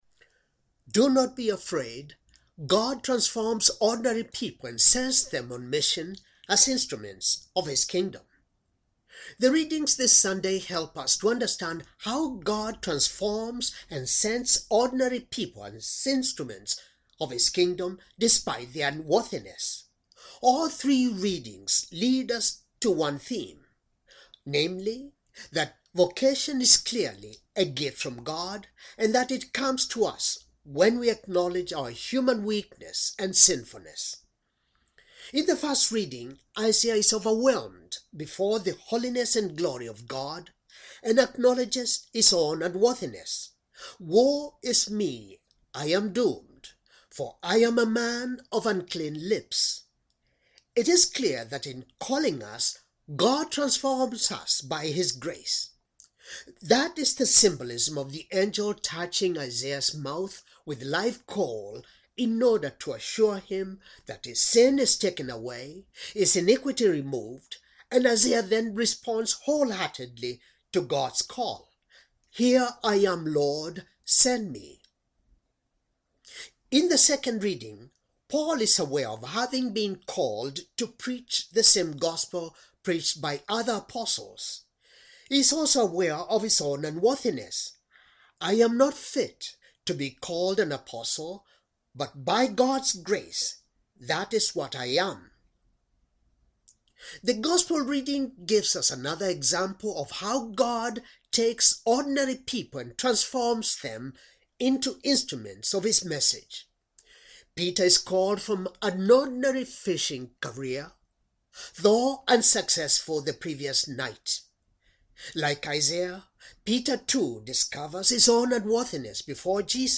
Homily for Fifth Sunday, Ordinary Time, Year c